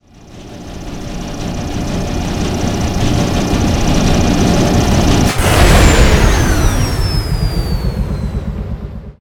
dronein.ogg